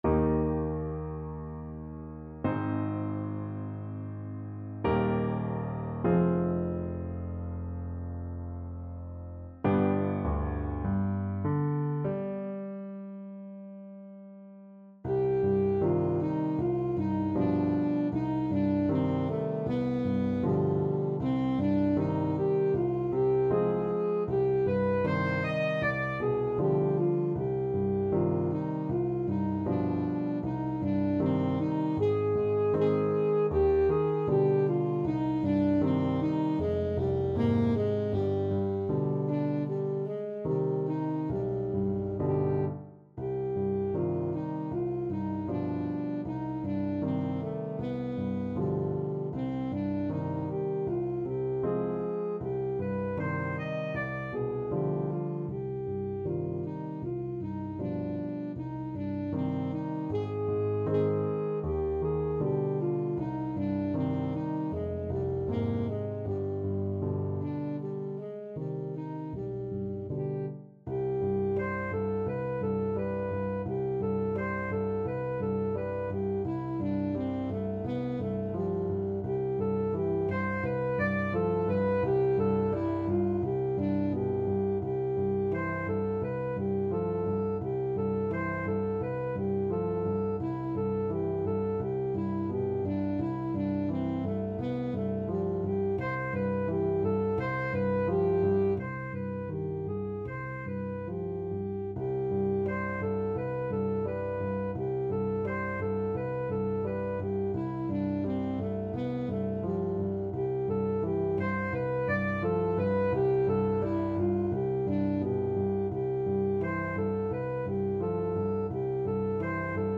Alto Saxophone version
Largo
4/4 (View more 4/4 Music)
Classical (View more Classical Saxophone Music)